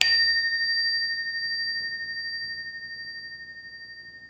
question_markTermékkör Pengetős csengő
Retro hatású csengő, egyszerű mechanikával, erős hanggal.